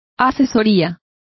Complete with pronunciation of the translation of consultancy.